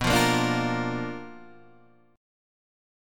BmM7b5 chord {7 8 8 7 6 6} chord